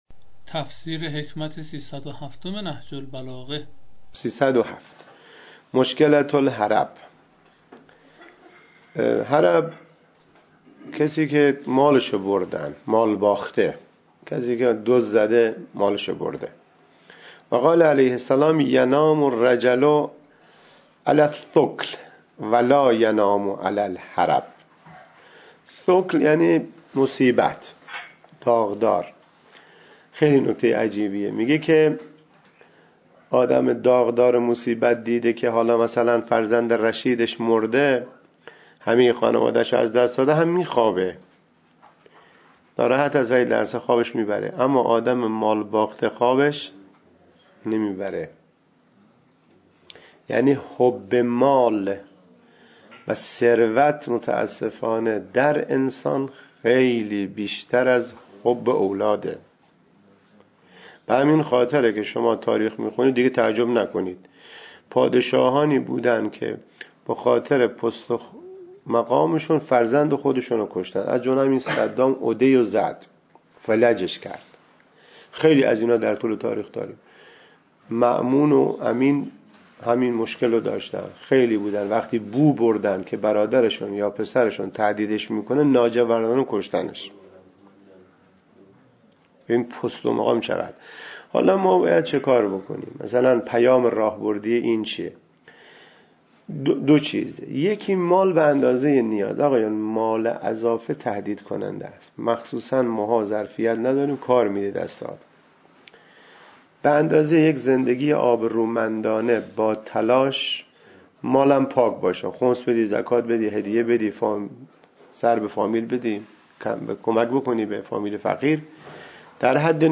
تفسیر صوتی حکمت 307 نهج البلاغه
تفسیر-صوتی-حکمت-307-نهج-البلاغه-C.mp3